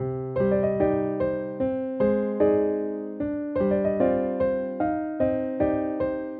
Tag: 150 bpm Trap Loops Piano Loops 1.08 MB wav Key : D